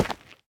Minecraft Version Minecraft Version snapshot Latest Release | Latest Snapshot snapshot / assets / minecraft / sounds / block / basalt / step6.ogg Compare With Compare With Latest Release | Latest Snapshot
step6.ogg